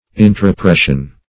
Intropression \In`tro*pres"sion\, n. Pressure acting within.